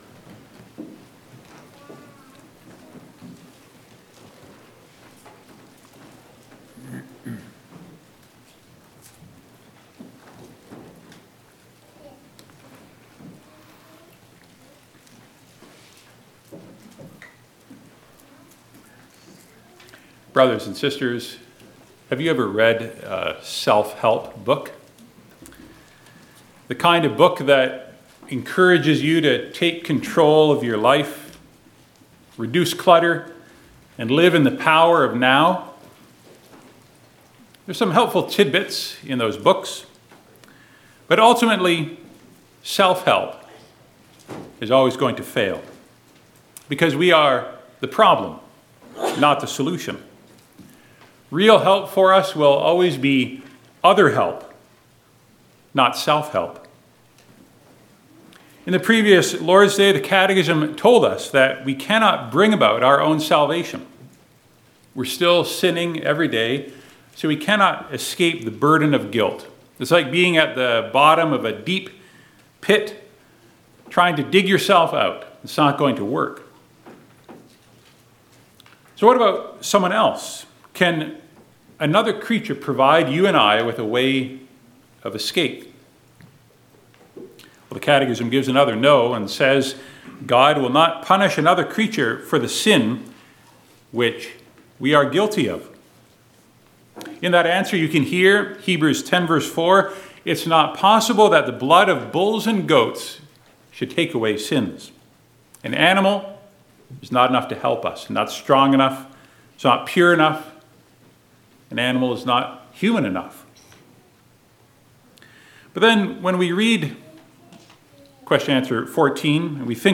Passage: Lev 16:1-34 Service Type: Sunday Afternoon